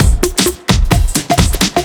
OTG_TripSwingMixC_130a.wav